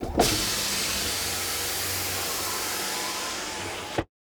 Car Power Window Down Sound
transport
Car Power Window Down